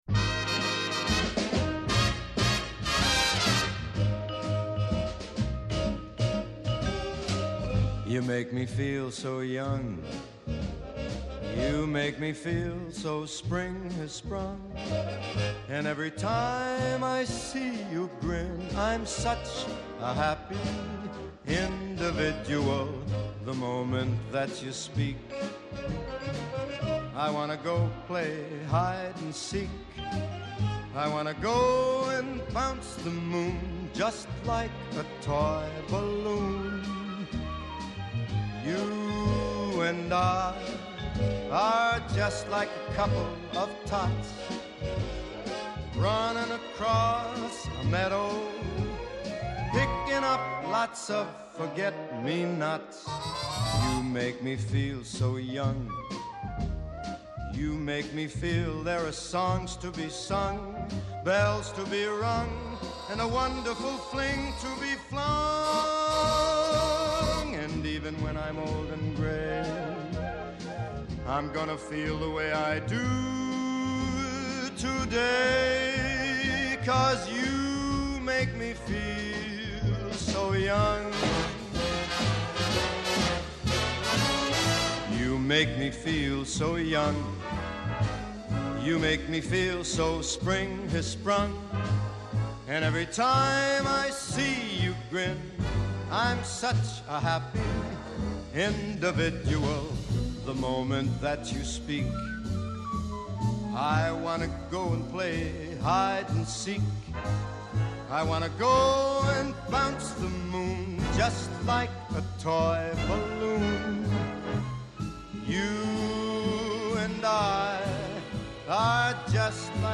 -Ο Φίλιππος Σαχινίδης, οικονομολόγος, πρώην υπουργός Οικονομικών